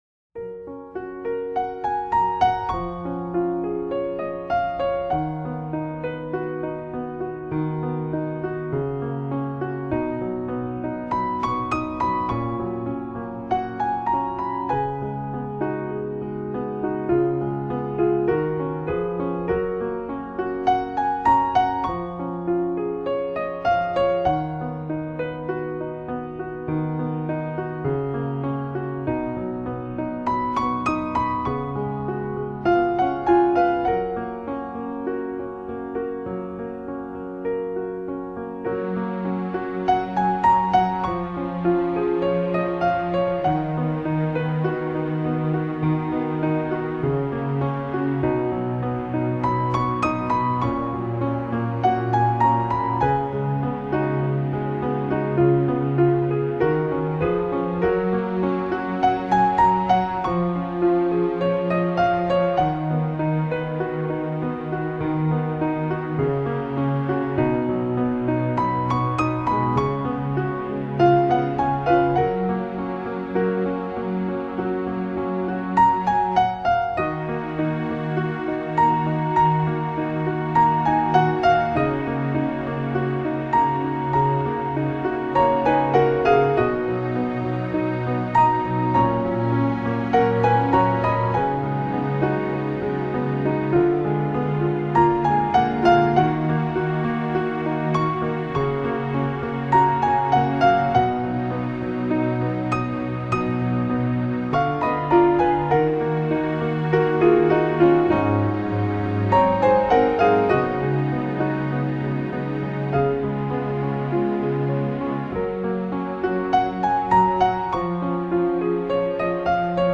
Genre: New Age, Neo Classical, Solo piano